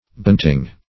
Search Result for " bayoneting" : The Collaborative International Dictionary of English v.0.48: Bayonet \Bay"o*net\, v. t. [imp.
bayoneting.mp3